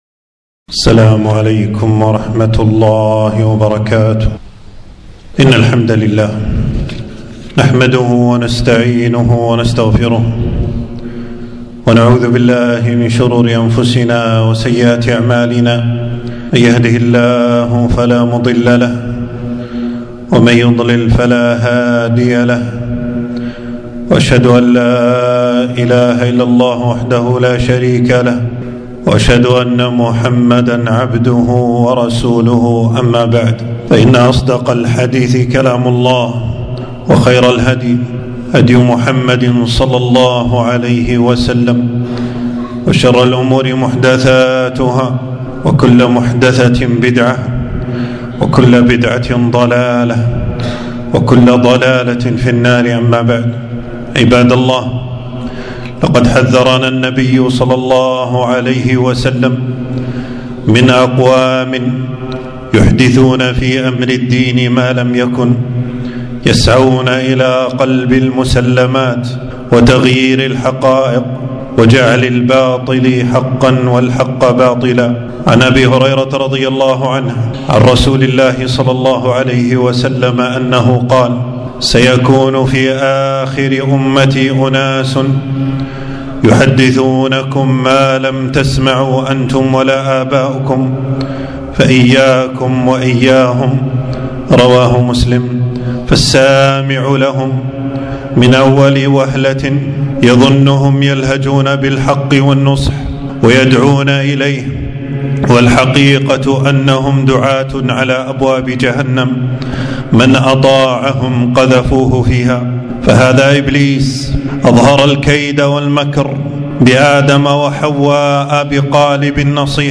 خطبة - الحذر من مذهب الخوارج ومافيه من خطر